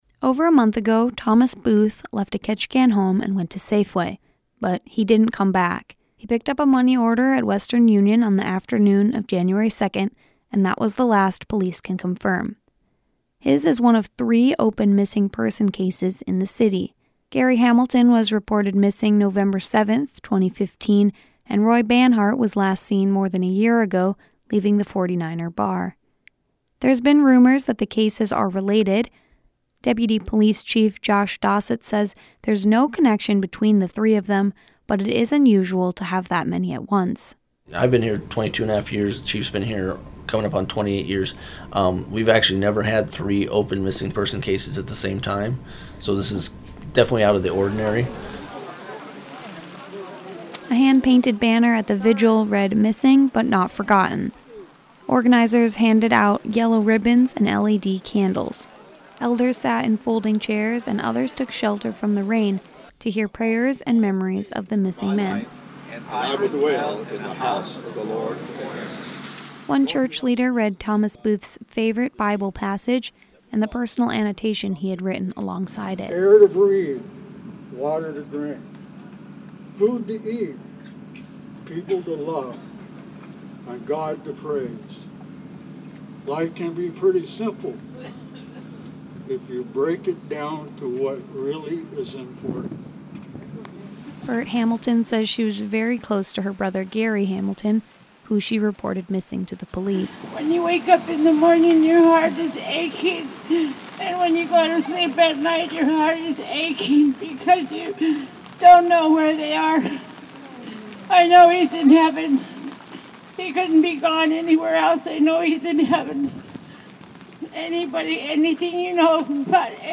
Last week, under temporary tents in The Plaza mall parking lot, more than 100 people gathered for a public prayer vigil for local missing people.